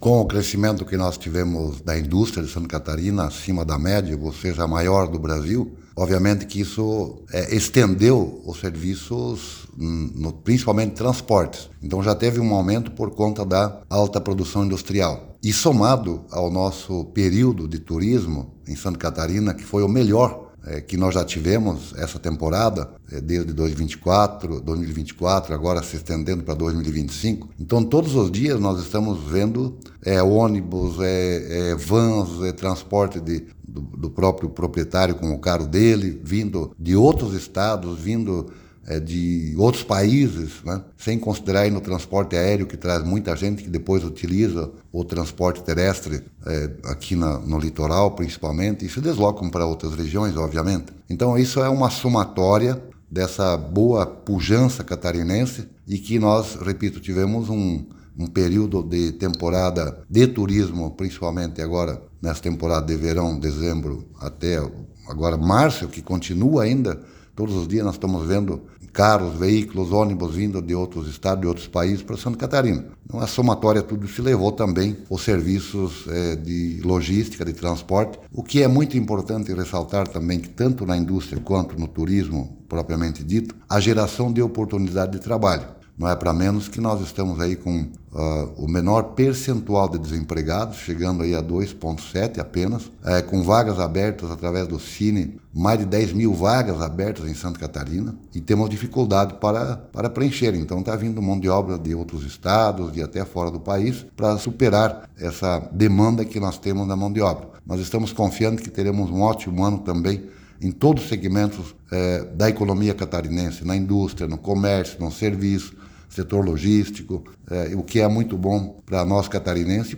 O secretário de Estado de Indústria, Comércio e Serviço, Silvio Dreveck,  destaca o transportes de passageiros, considerando a grande temporada de verão que ocorre em Santa Catarina e a chegada de milhares de turistas nacionais e estrangeiros: